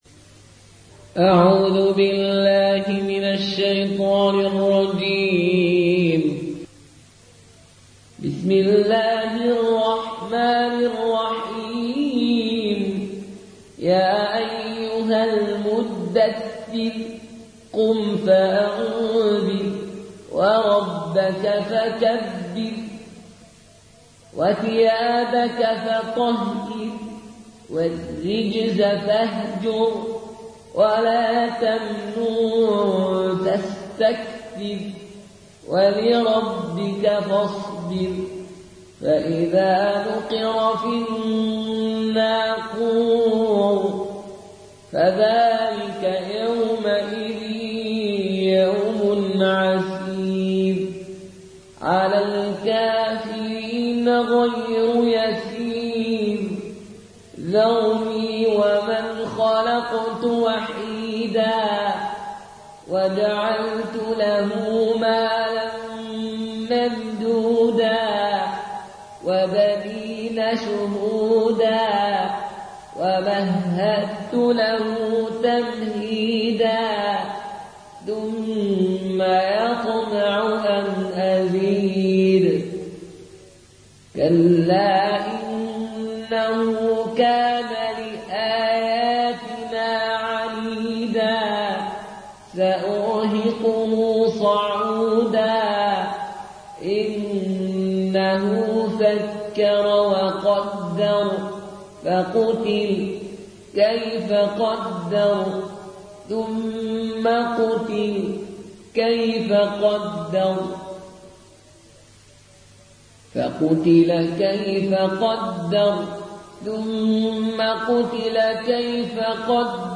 قالون عن نافع